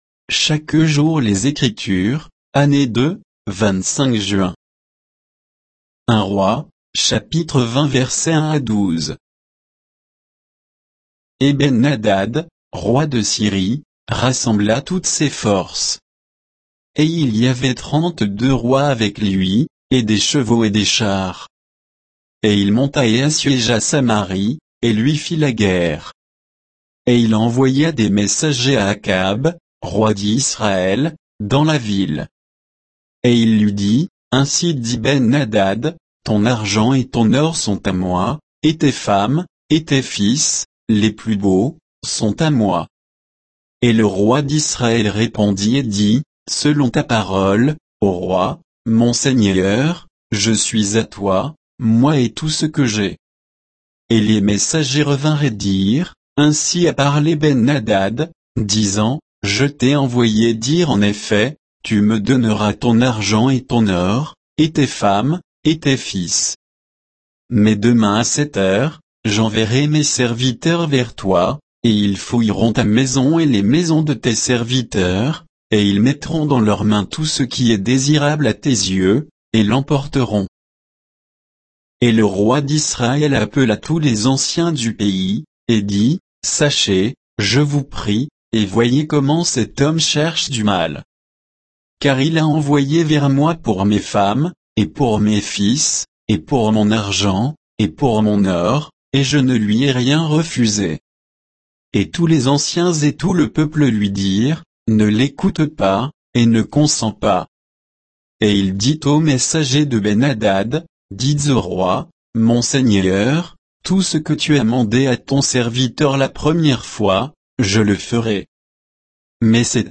Méditation quoditienne de Chaque jour les Écritures sur 1 Rois 20, 1 à 12